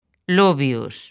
Transcripción fonética
ˈloβjo̝s